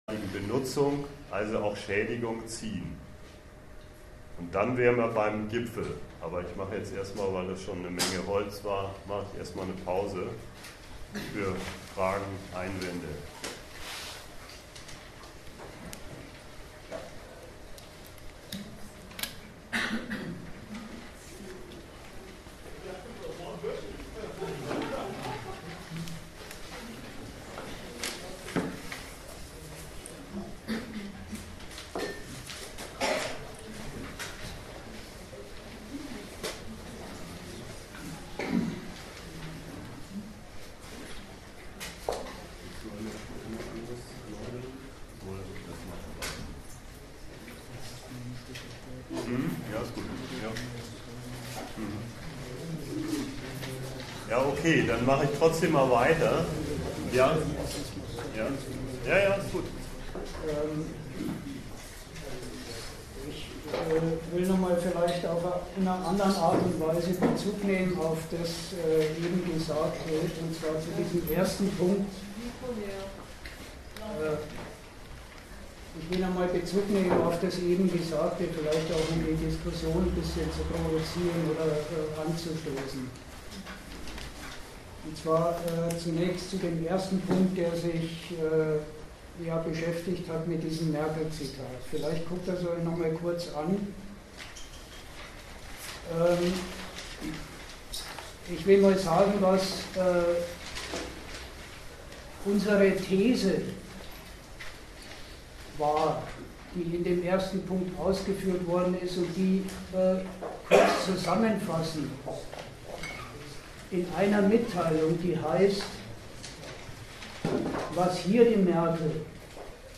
Worum es bei solchen Weltwirtschaftsgipfeln geht und was den aktuellen G20-Gipfel so speziell macht, darüber wurde am 20.6.16 diskutiert.